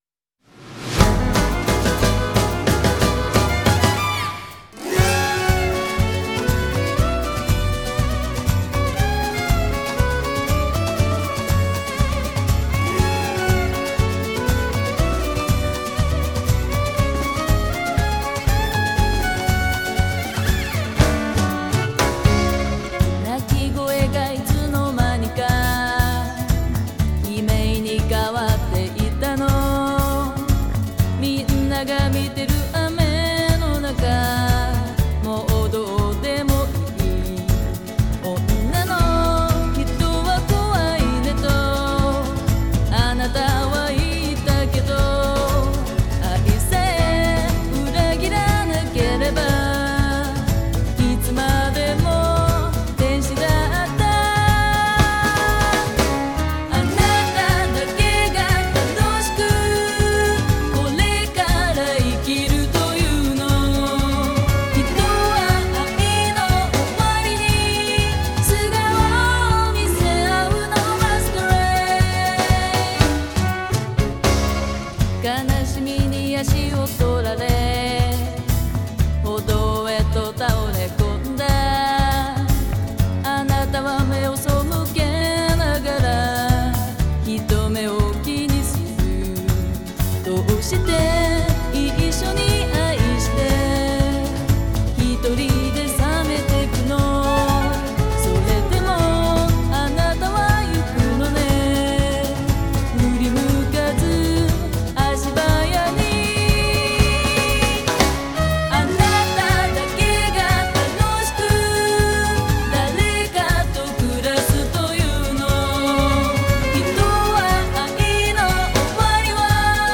Genre: DOMESTIC(J-POPS).